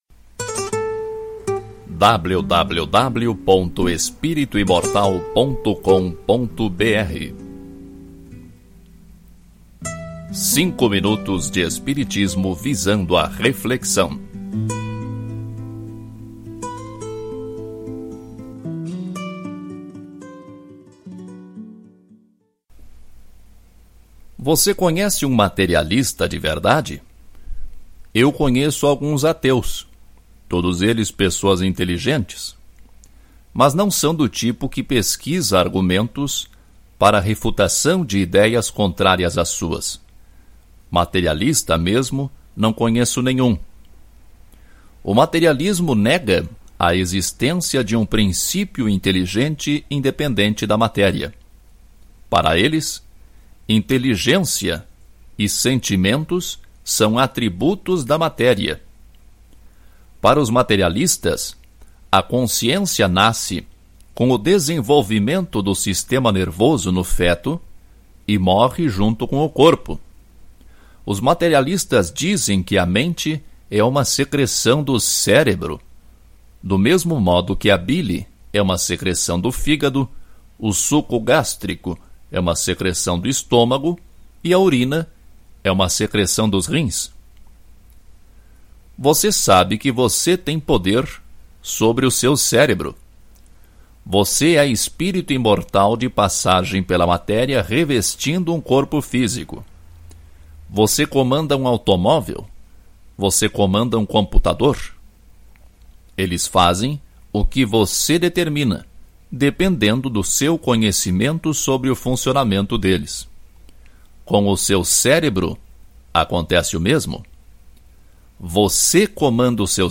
Ouça este artigo na voz do autor